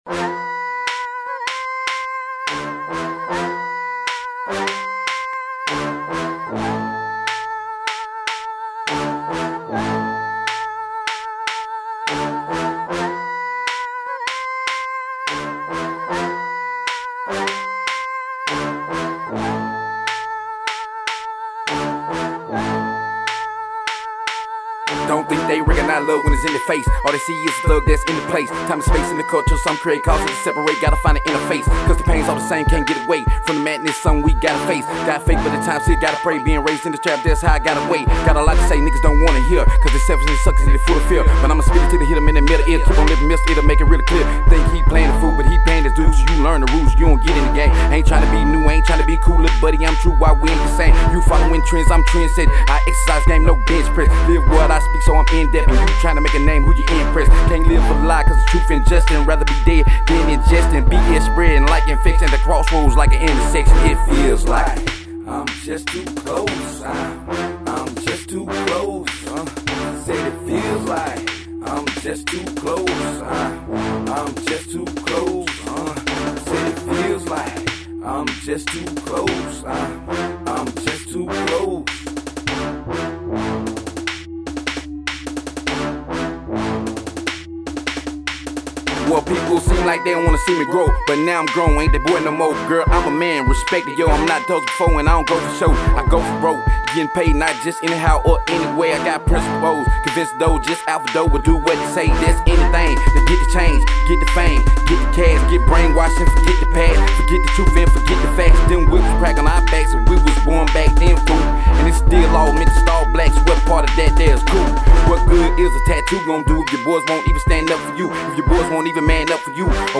Hiphop
Intensely lyrical and in depth track